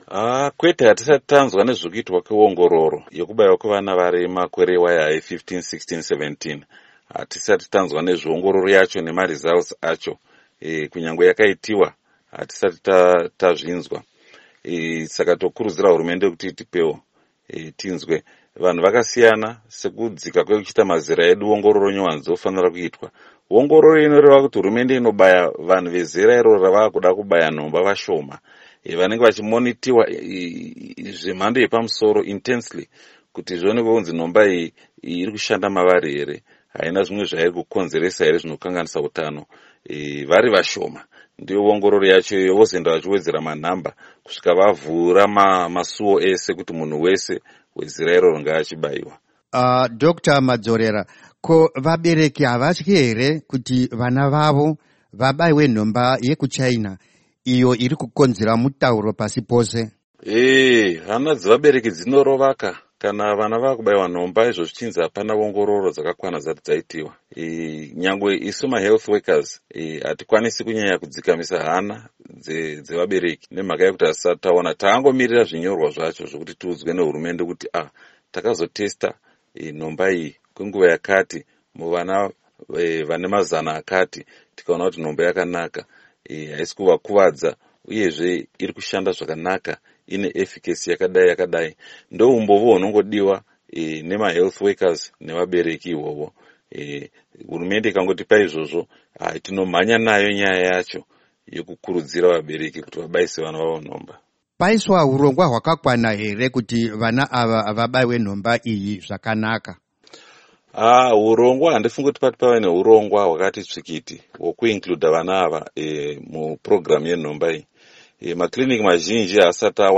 Hurukuro naDr Henry Madzorera